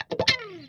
WAHWAHCHUNK3.wav